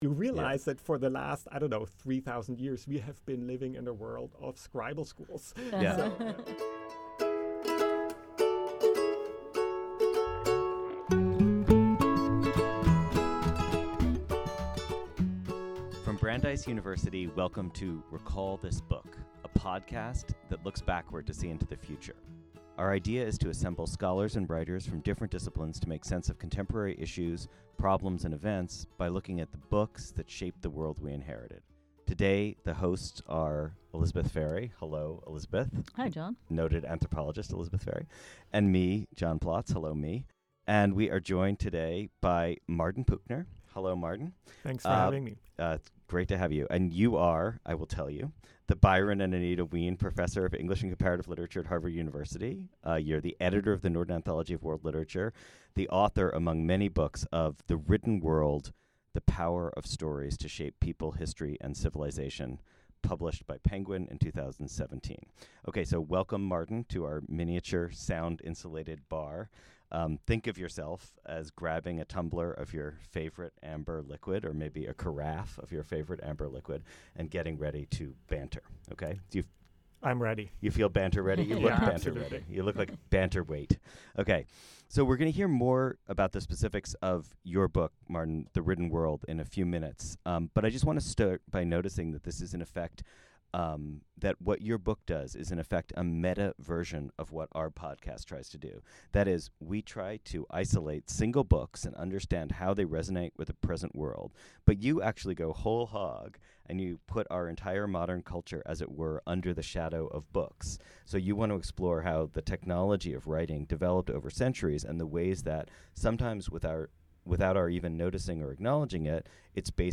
Come for the discussion of writing, stay for the impressions of Gollum!